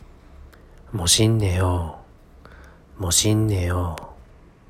モシンネヨ
【멋있네요（モシンネヨ）の発音】